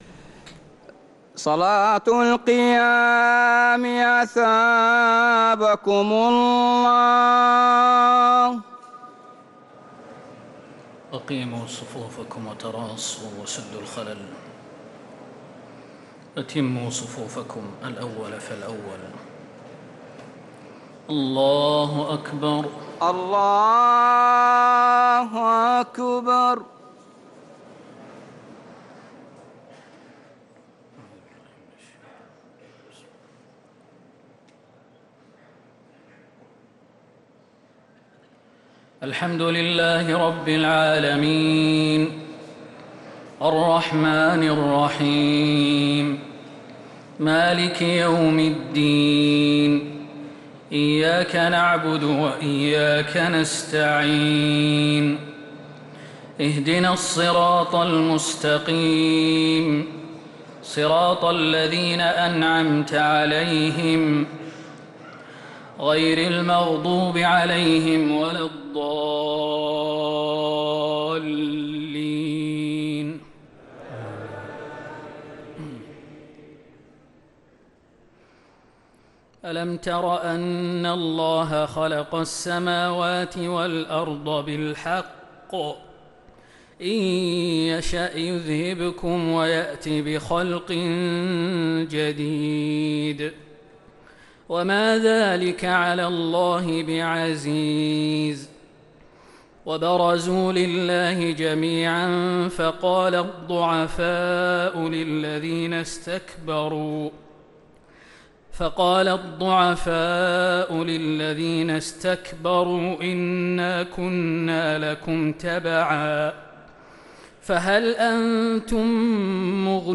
تراويح ليلة 18 رمضان 1446هـ من سورتي إبراهيم (19-52) و الحجر كاملة | Taraweeh 18th night Ramadan 1446H Surat Ibrahim and Al-Hijr > تراويح الحرم النبوي عام 1446 🕌 > التراويح - تلاوات الحرمين